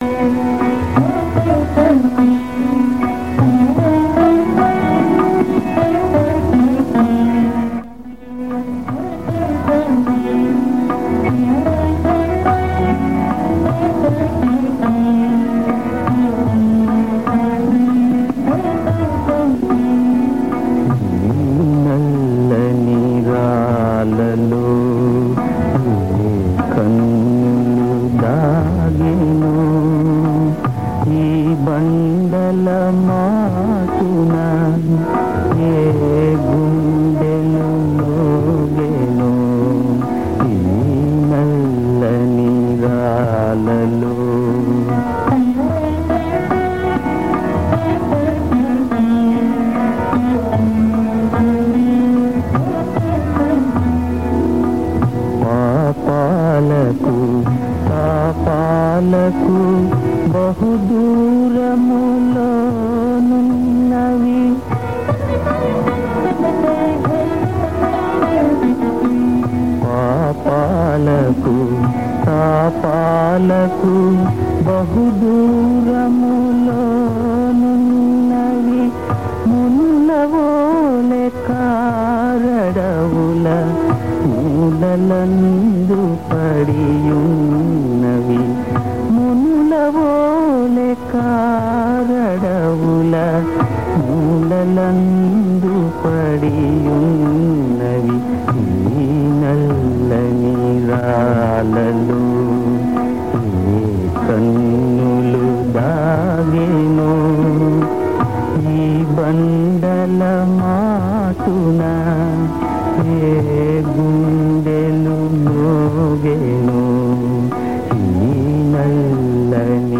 Ragam - Chala naata(36)